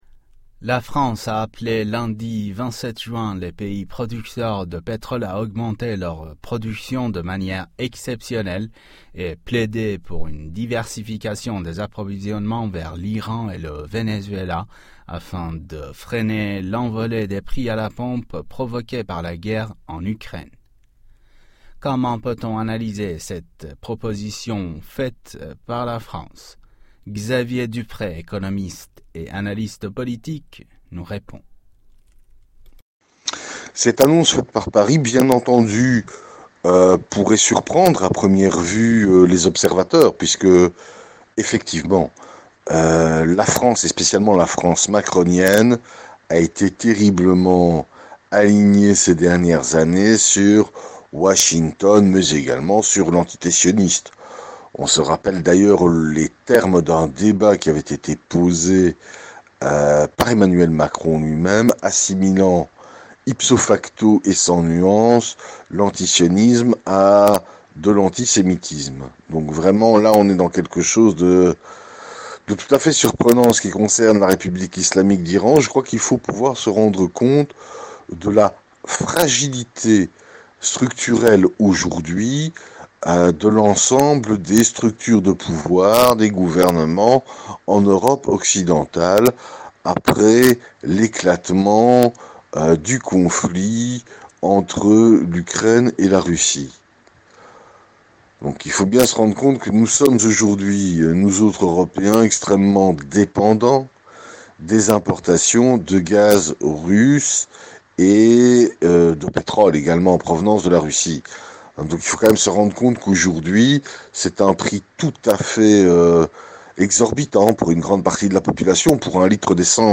économiste et analyste politique s'exprime sur le sujet.